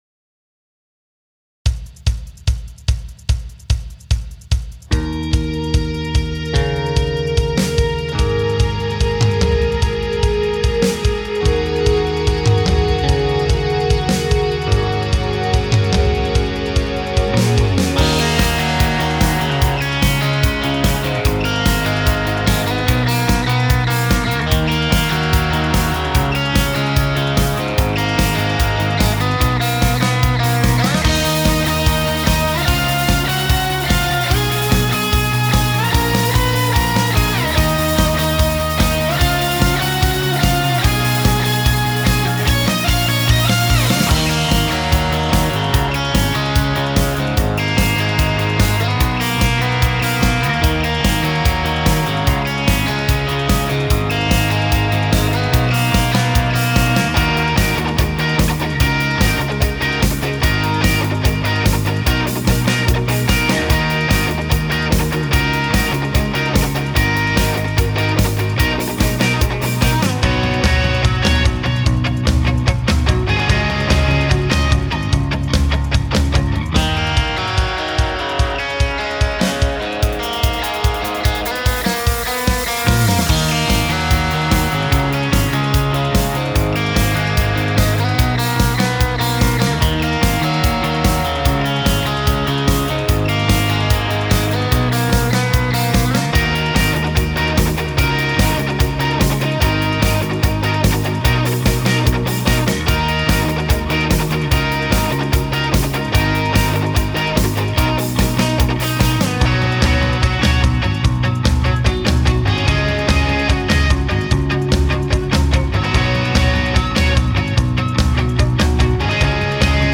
BPM : 147
Tuning : Eb
Without vocals